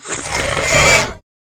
Divergent / mods / Soundscape Overhaul / gamedata / sounds / monsters / chimera / idle_2.ogg